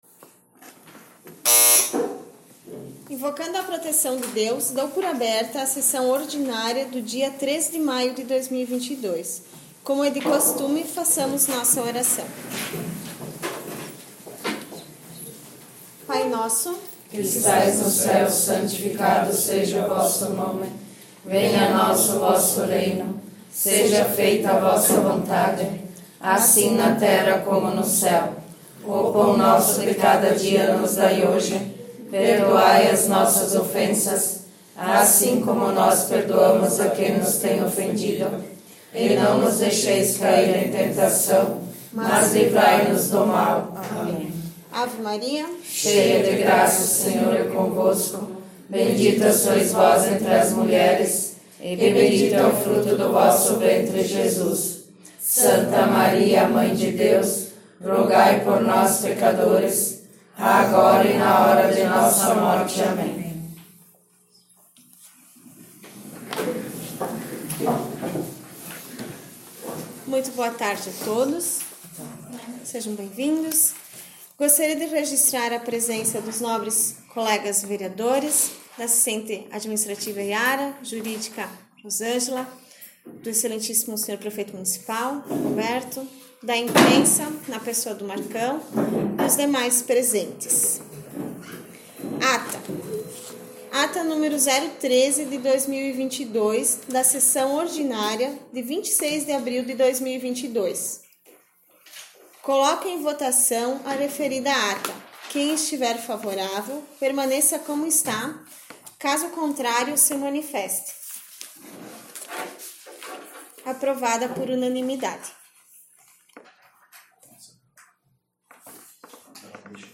11 - Sessão Ordinária 03 maio 2022 — Câmara Municipal de Boa Vista do Sul
Áudio/Gravação das Sessões da Casa Legislativa Todos os Áudios ÁUDIO SESSÕES 2021 ÁUDIO DAS SESSÕES 2020 ÁUDIO DAS SESSÕES 2019 ÁUDIO DAS SESSÕES 2022 7 - Sessão Ordinária 22 de março 11 - Sessão Ordinária 03 maio 2022